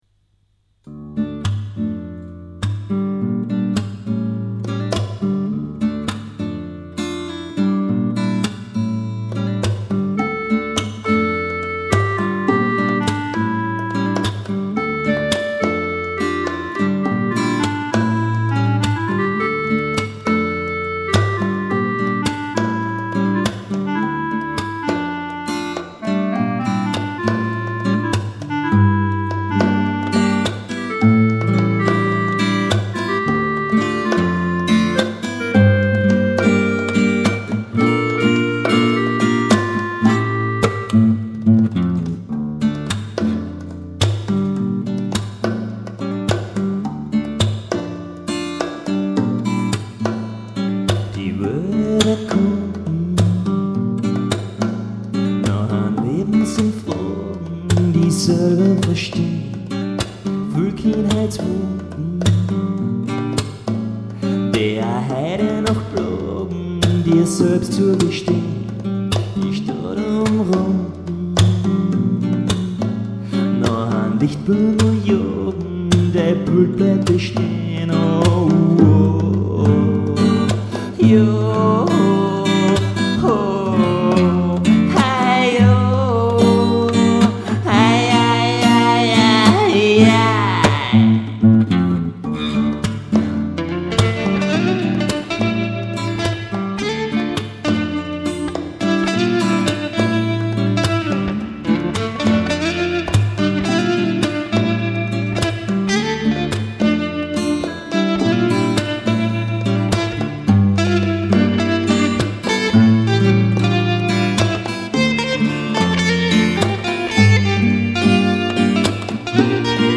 vocals, acoustic and electric guitar, synth-clarinette
Bongos